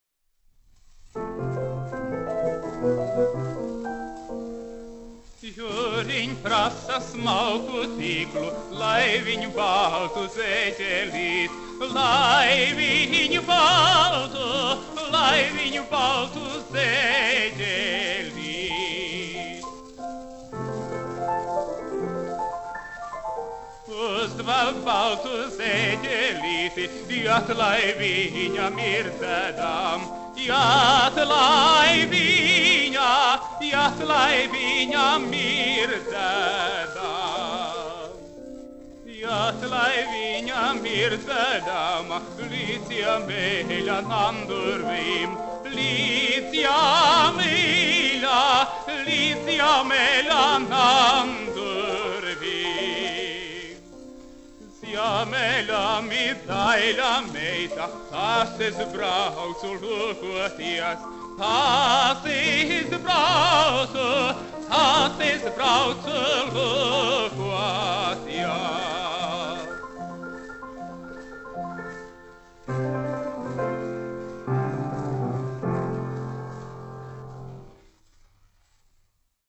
1 skpl. : analogs, 78 apgr/min, mono ; 25 cm
Dziesmas (augsta balss) ar klavierēm
Skaņuplate
Latvijas vēsturiskie šellaka skaņuplašu ieraksti (Kolekcija)